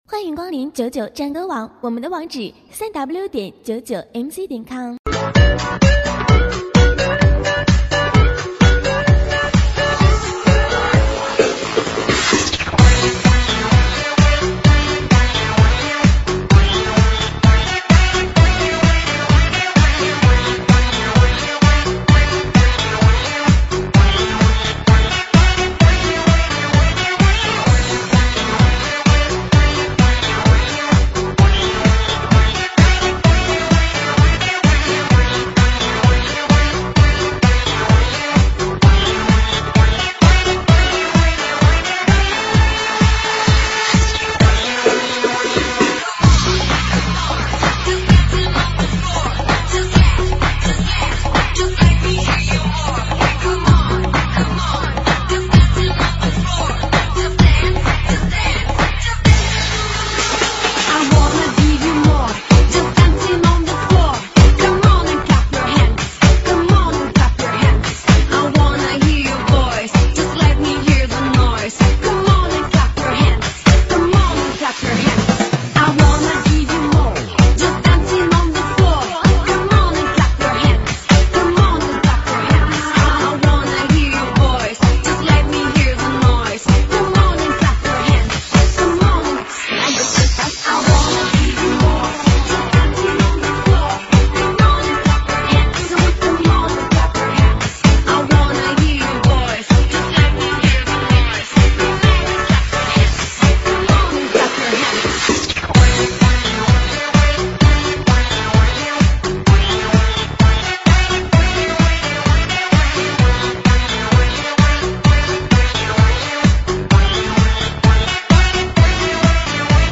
英文舞曲